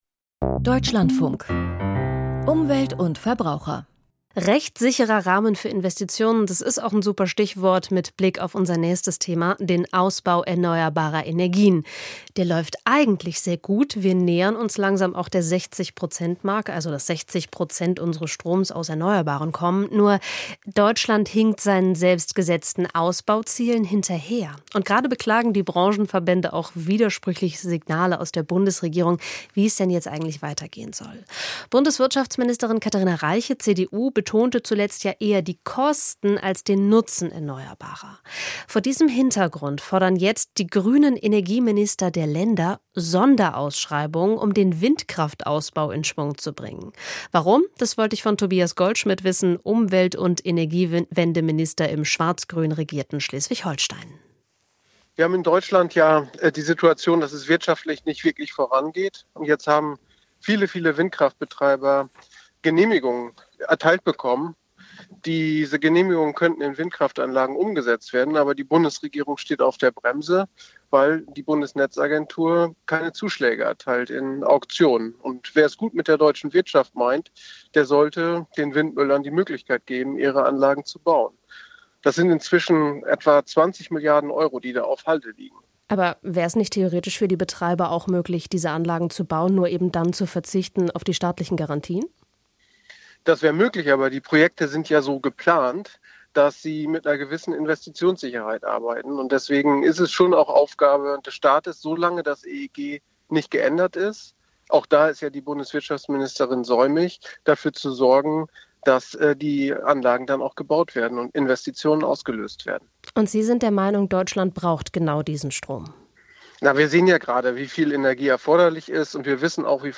Interview T. Goldschmidt (Energieminister SH): Sonderausschreibungen für Wind?